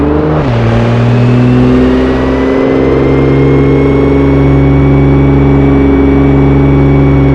toyfj_gear.wav